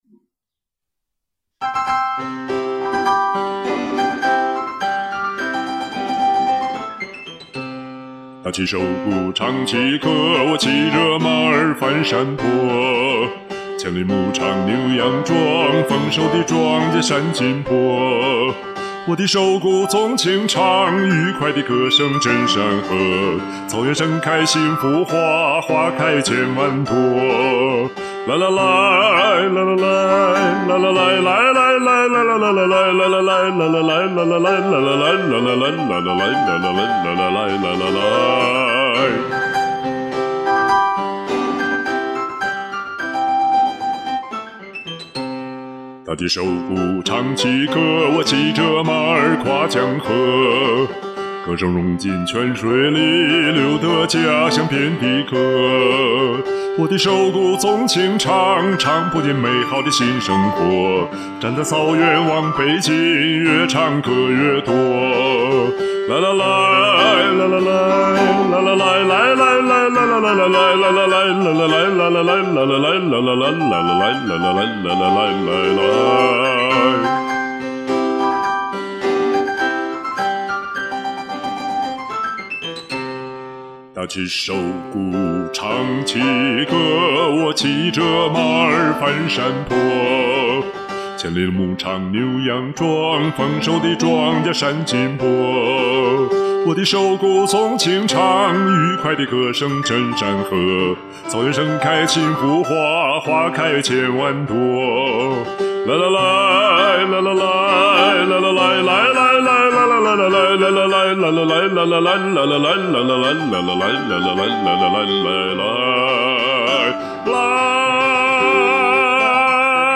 很久沒錄歌了，這個周末總算湊合了一首。
自己一試，完了，嘴裡拌蒜！